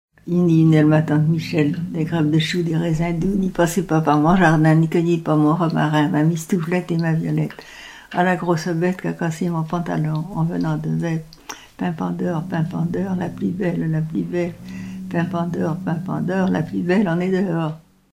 Enfantines - rondes et jeux
Pièce musicale éditée